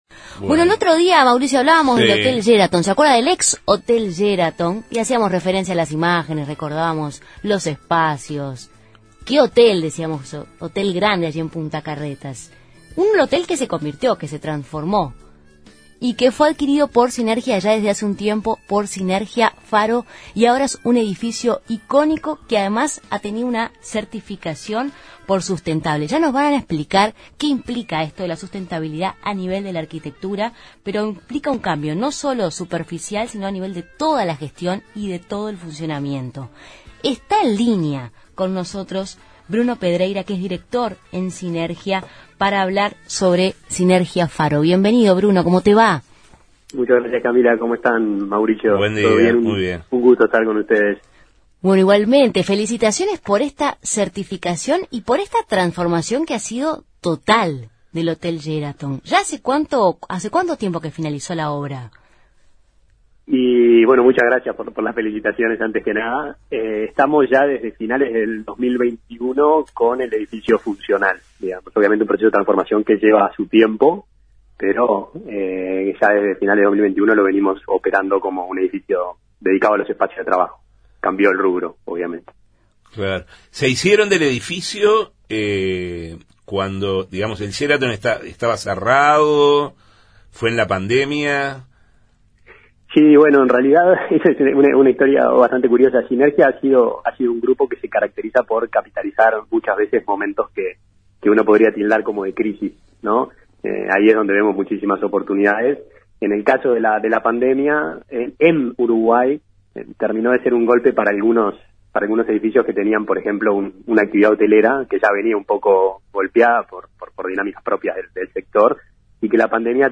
Entrevistado por Justos y pecadores